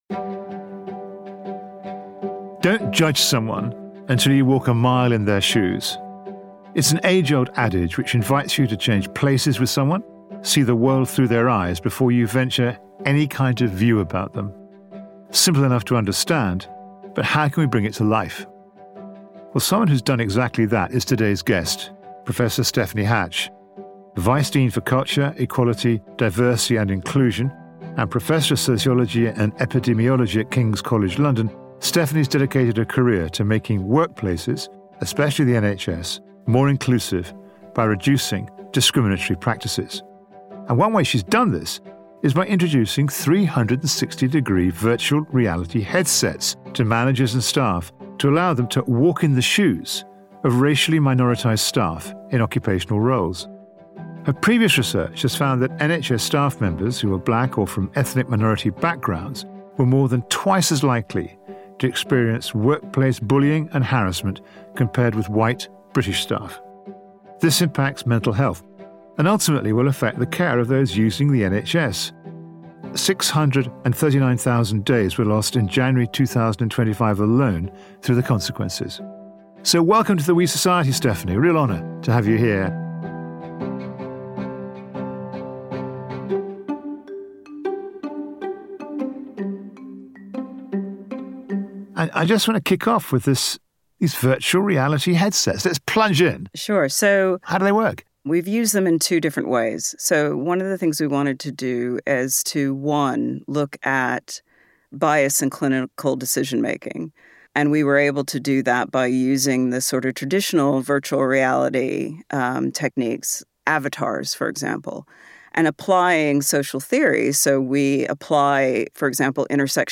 conversation.